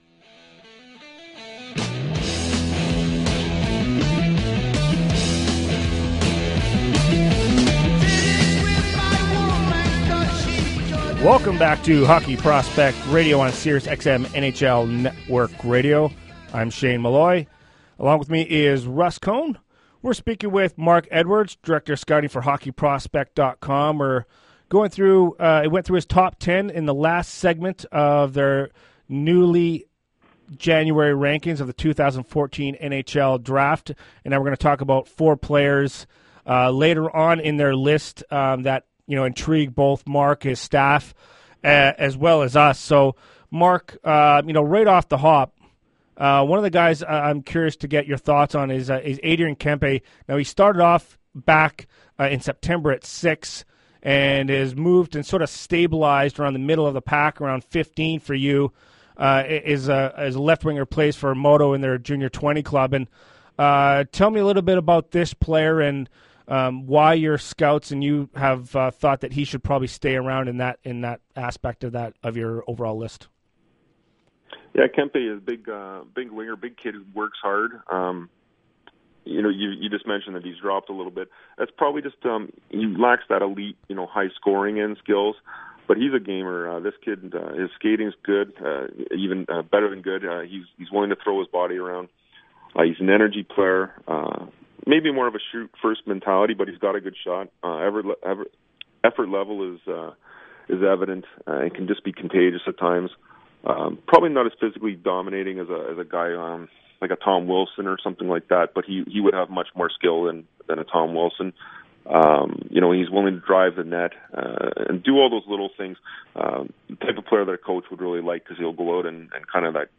On this weeks Hockey Prospects radio show on NHL Network radio